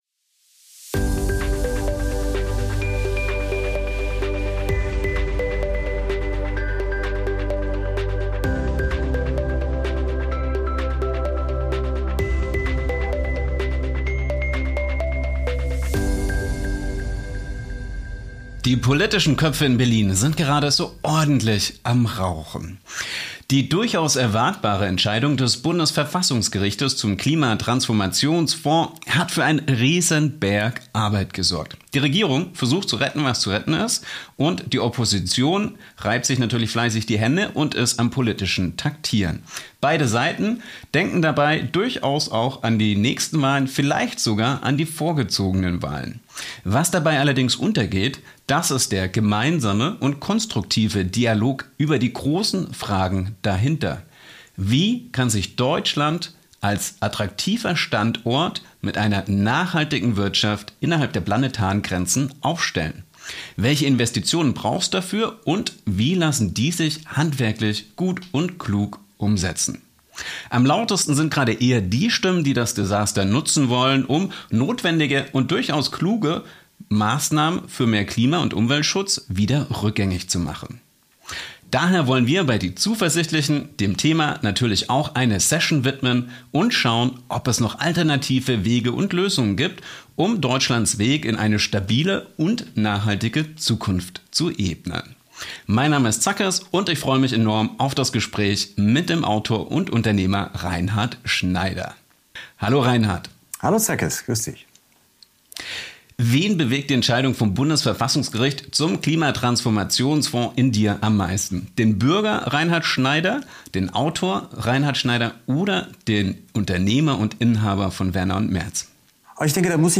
Das Gespräch zur aktuellen Lage in Deutschland.